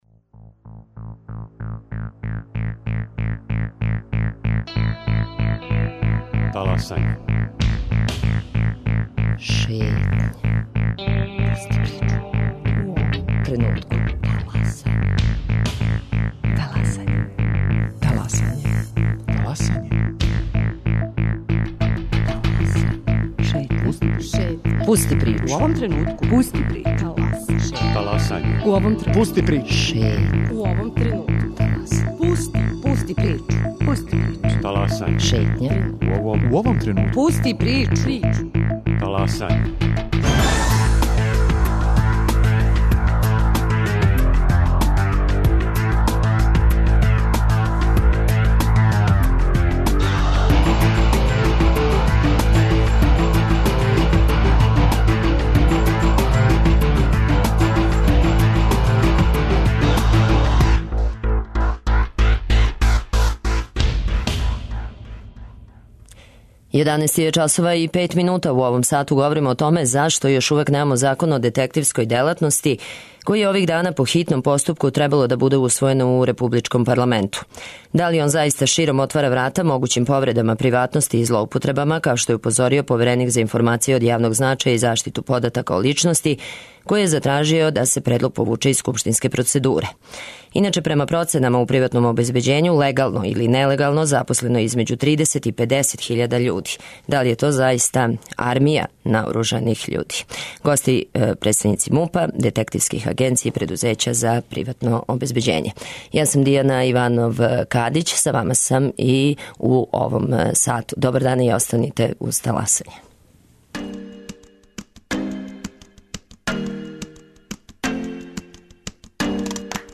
Гости: представници МУП-а и детективских агенција.